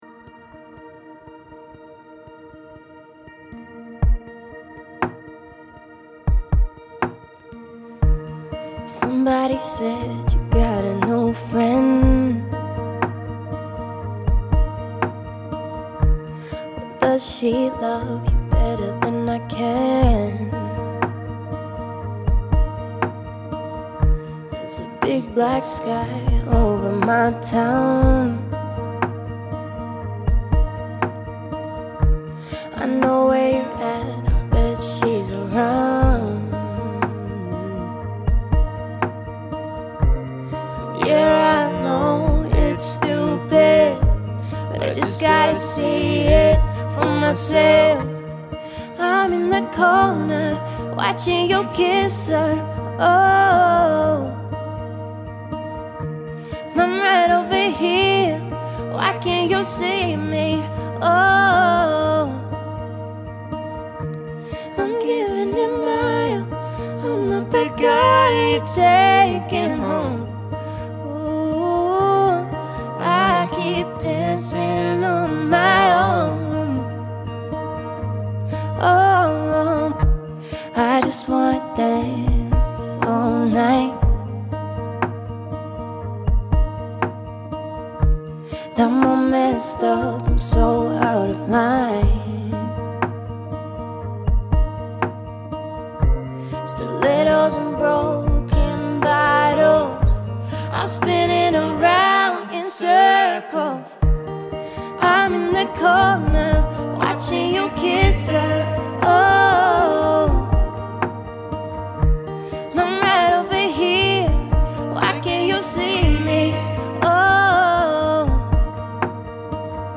Dual Vocals | Looping | 2 Guitars | DJ